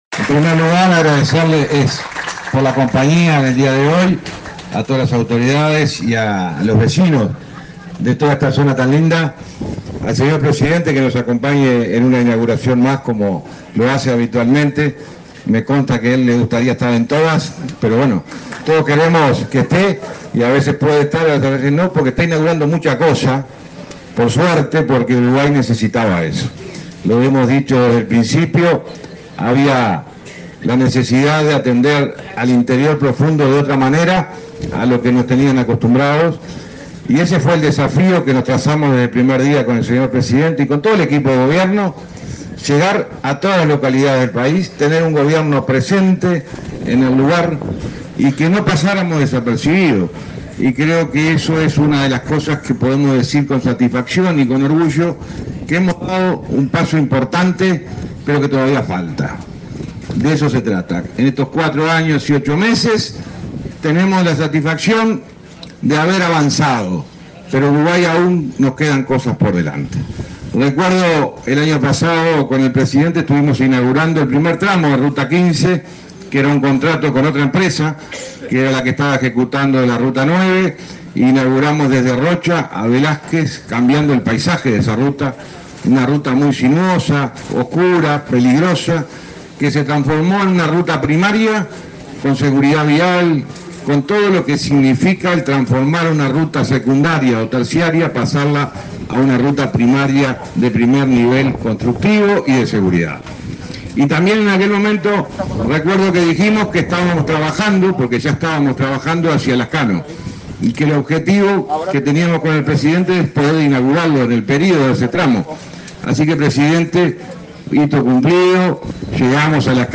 Palabras del ministro del MTOP, José Luis Falero
Palabras del ministro del MTOP, José Luis Falero 08/11/2024 Compartir Facebook X Copiar enlace WhatsApp LinkedIn El presidente de la República, Luis Lacalle Pou, participó, este 7 de noviembre, en la inauguración de obras en la ruta n.°15, en la localidad de Lascano, Rocha. En el evento disertó el titular del Ministerio de Transporte y Obras Públicas (MTOP), José Luis Falero.